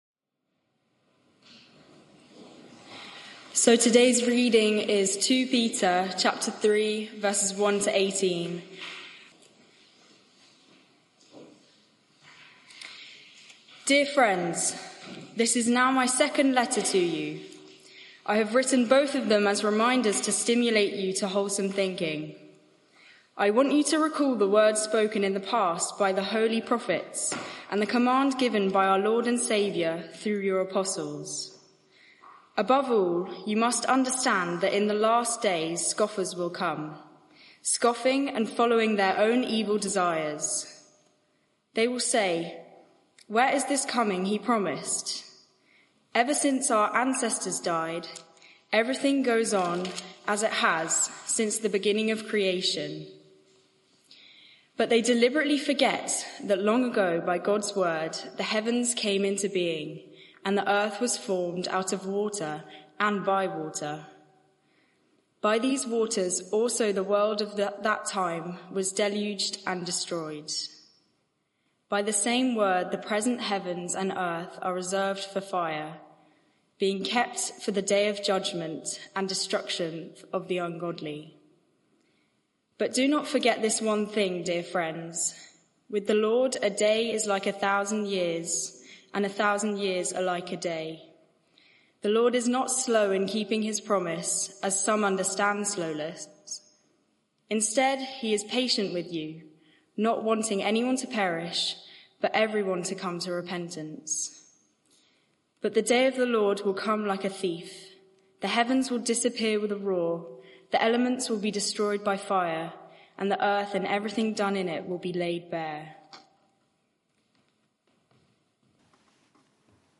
Media for 6:30pm Service on Sun 22nd Sep 2024 18:30 Speaker
Passage: 2 Peter 3:1-18 Series: Stable and growing Theme: Sermon (audio)